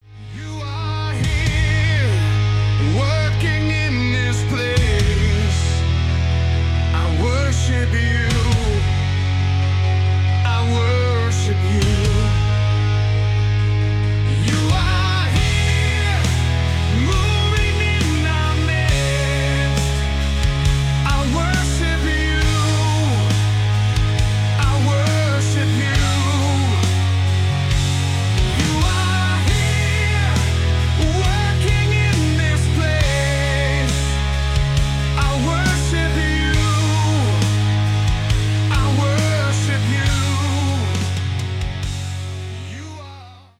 рок , христианский рок
тяжелый рок , cover